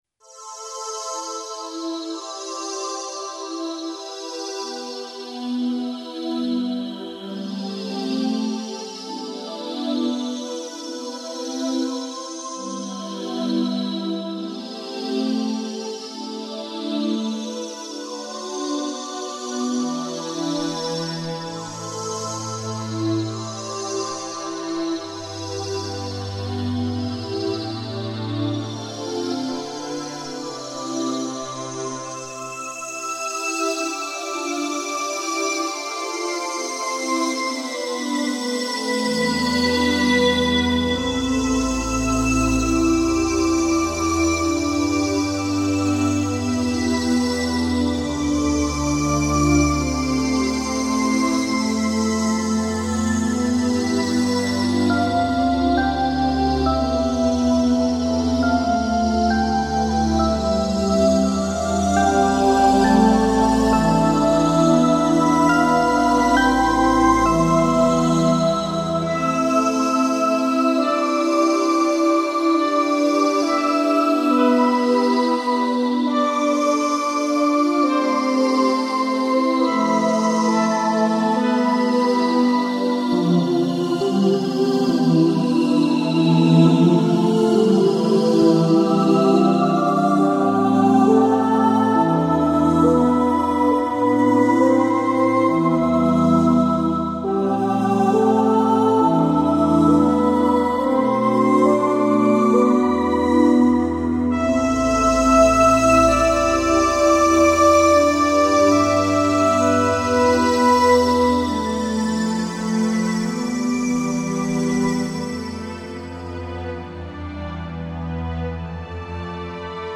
P3 23:55 min P3 is a large-scale piece scored for synthetic orchestra.